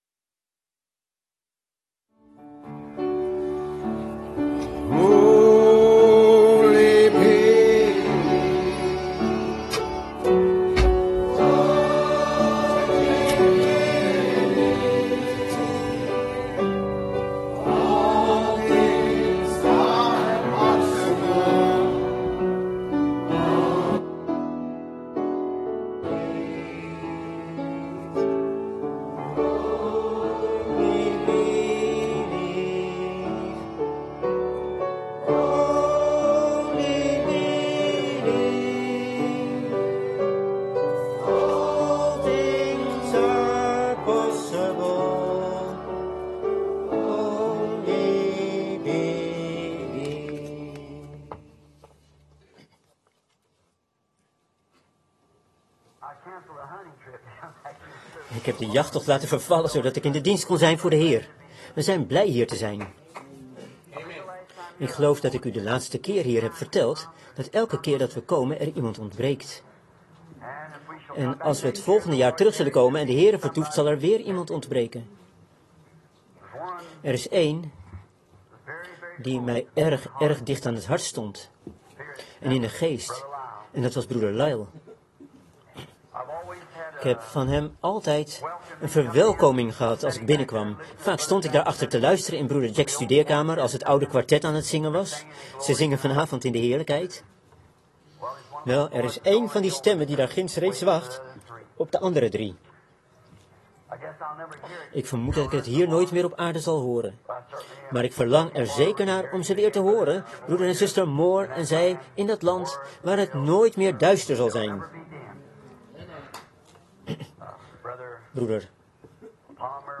Prediking
Locatie Life Tabernacle Shreveport , LA